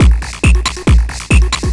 DS 138-BPM A3.wav